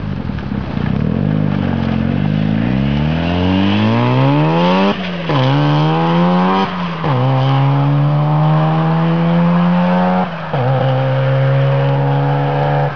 It's the sound only an enthusiast could love - that all too familiar brap-brap-brap of a well tuned bridge port rotary.
Outside an Rx-2 turbo